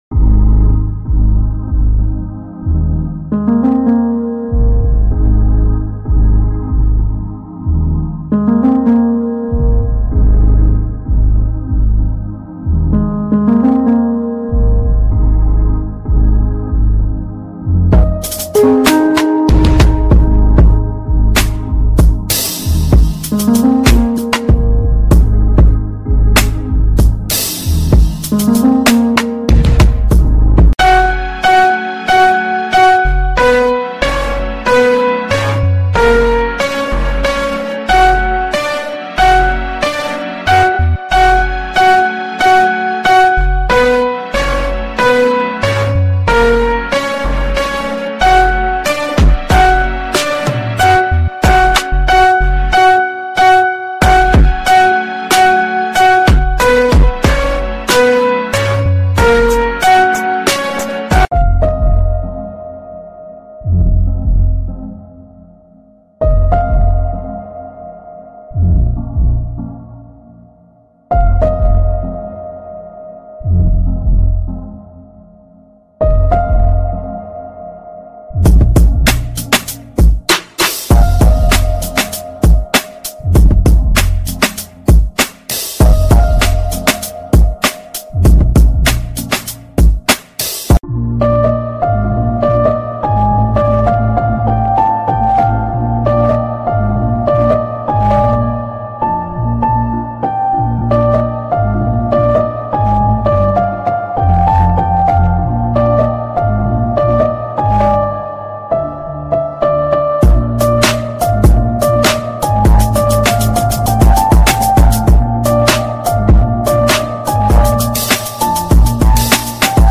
Walking Florence, Italy ｜ Florence sound effects free download